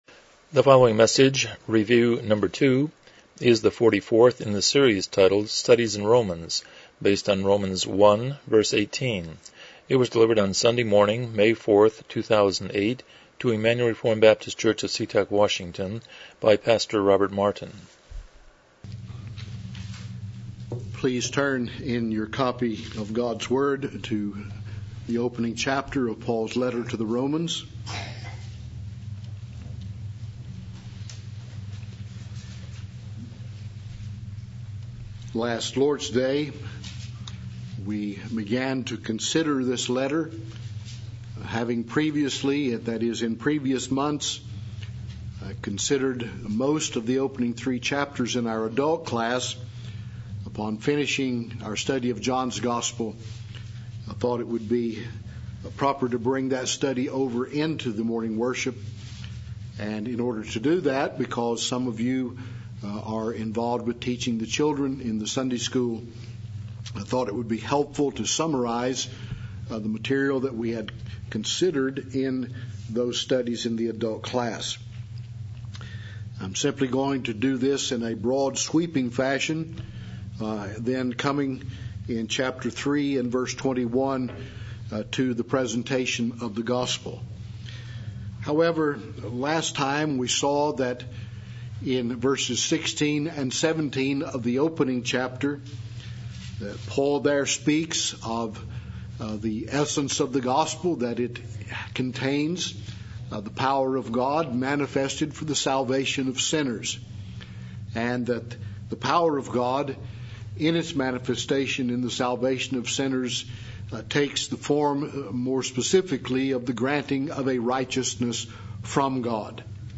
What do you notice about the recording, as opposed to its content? Romans 1:18 Service Type: Morning Worship « 01 Lessons from Creation